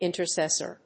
• / ìnṭɚsésɚ(米国英語)
• / ìntəsésə(英国英語)